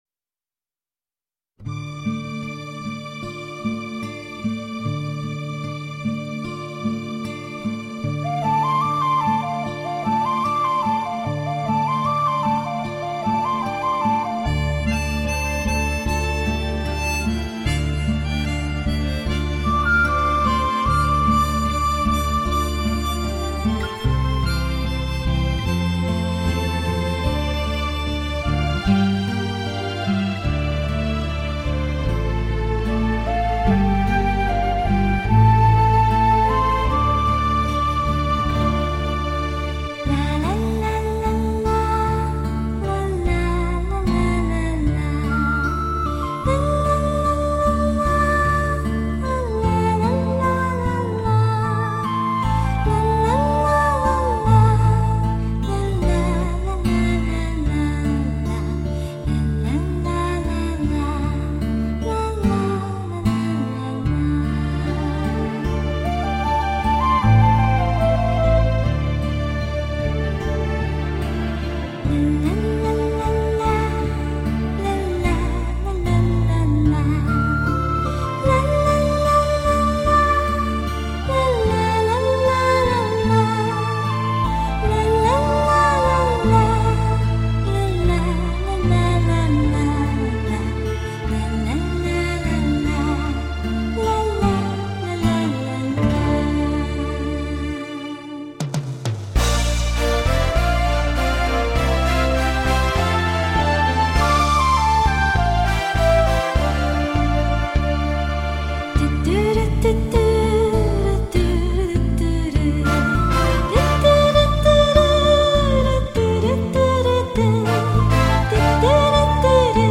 背景音乐